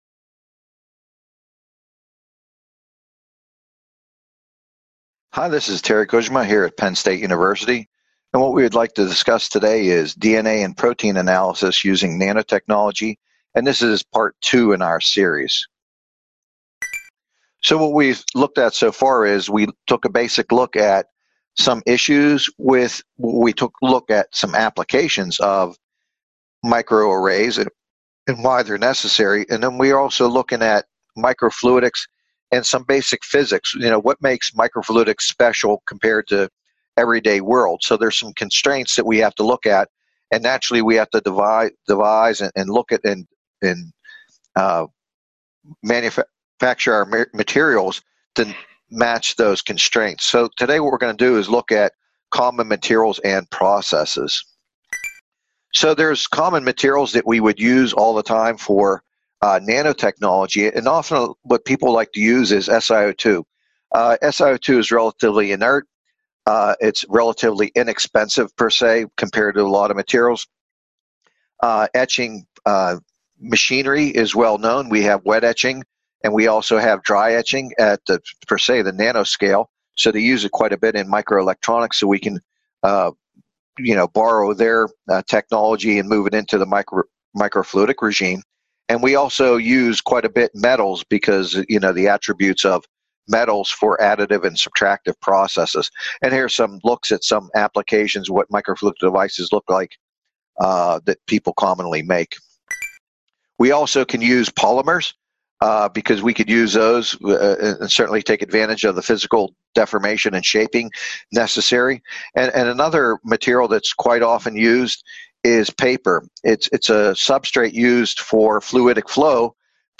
This video, provided by the Nanotechnology Applications and Career Knowledge Support (NACK) Center at Pennsylvania State University, is part one of a two-part lecture on materials modification in nanotechnology, specifically focusing the use of nanotechnology for DNA and protein analysis.